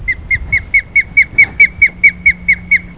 Osprey
OSPREYcall.wav